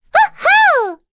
Rehartna-Vox_Happy6.wav